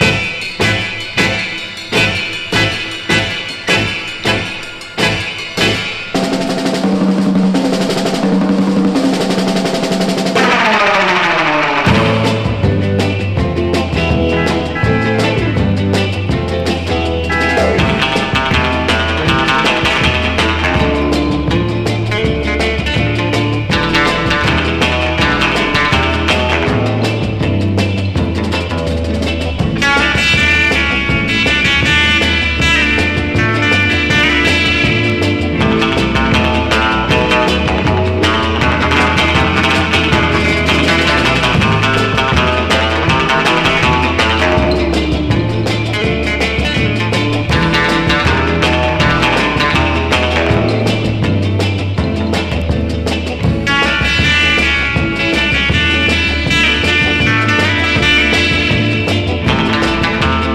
キュートに舞う彼女達のヴォーカル